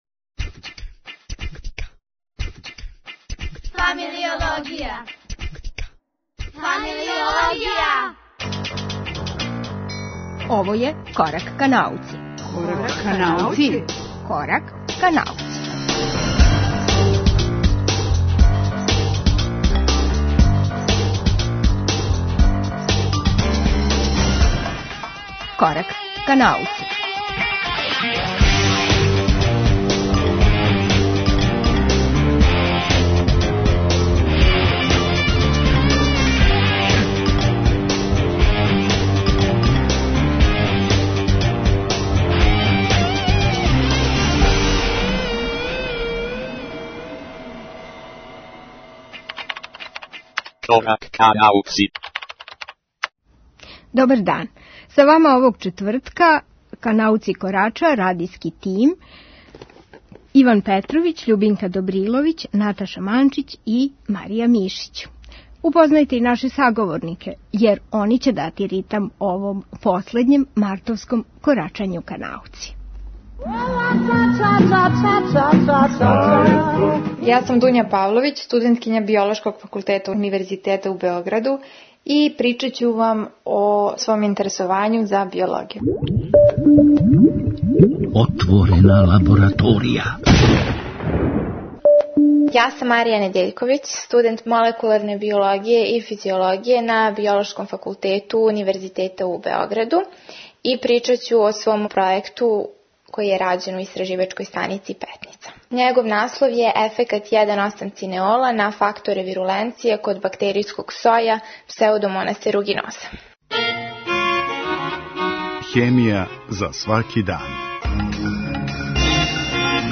Оне и усмеравају ток разговора са сарадницима на пројекту Terrfica окренутом климатским променама у овом Кораку ка науци.